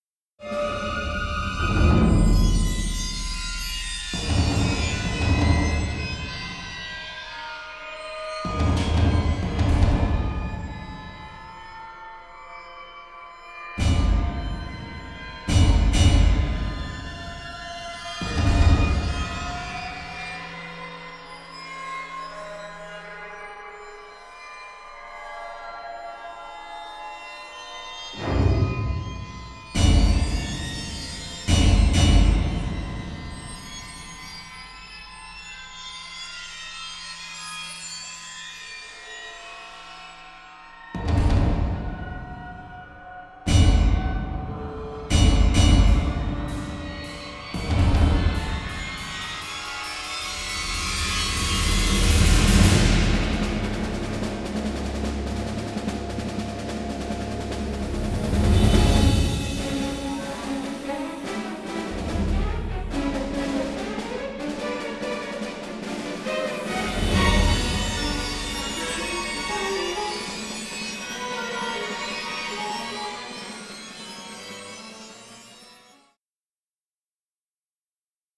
WWII action score
mastered from two-track stereo session masters